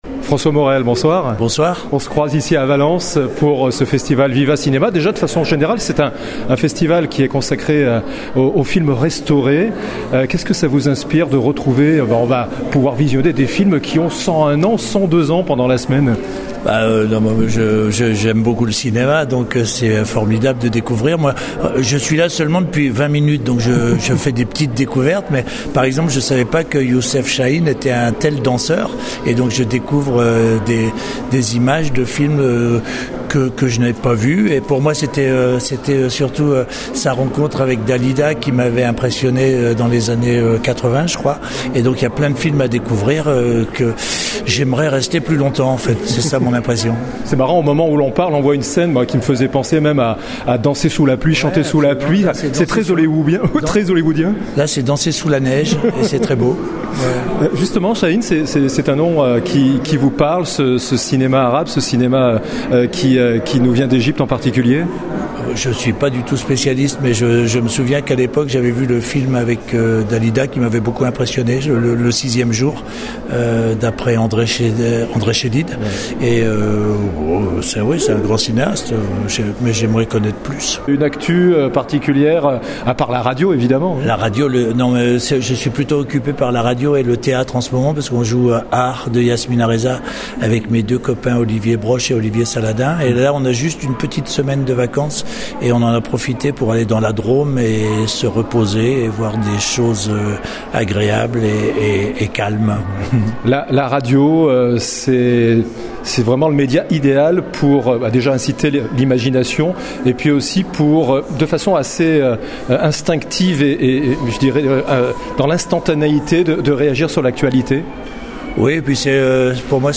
2. Podcasts cinéma : interviews | La Radio du Cinéma
François Morel à Viva Cinéma